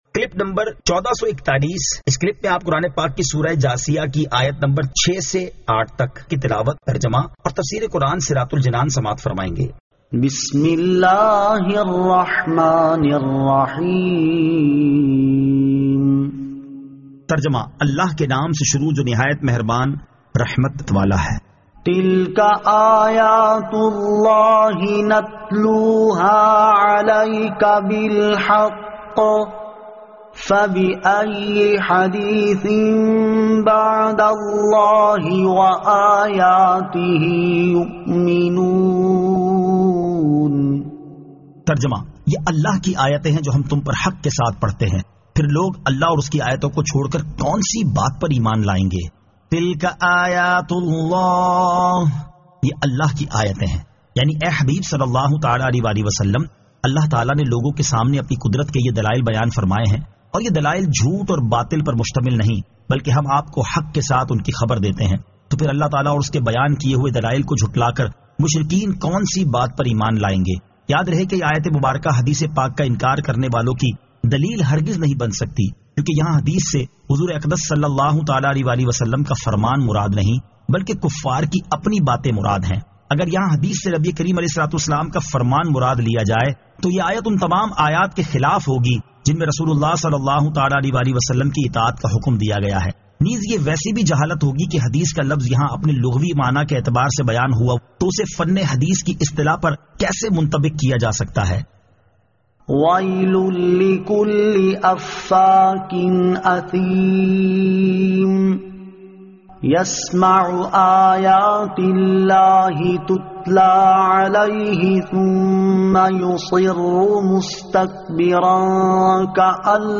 Surah Al-Jathiyah 06 To 08 Tilawat , Tarjama , Tafseer